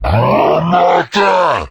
izlome_idle_3.ogg